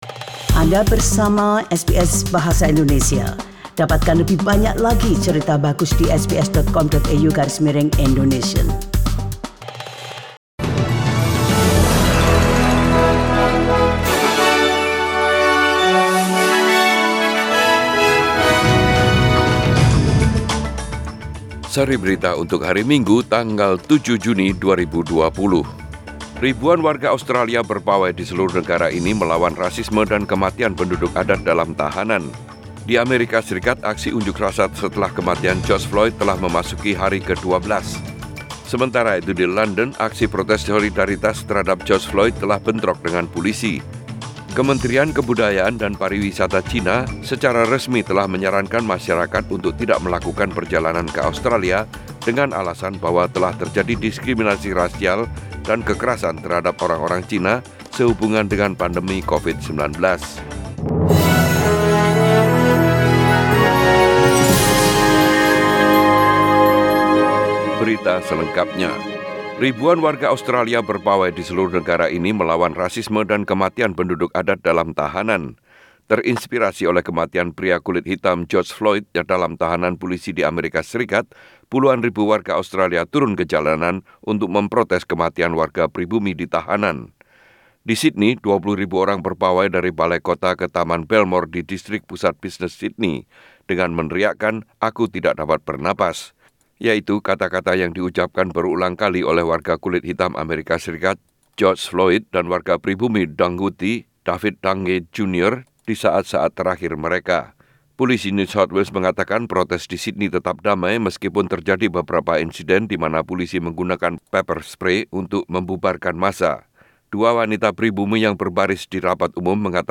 SBS Radio News in Bahasa Indonesia - 7 June 2020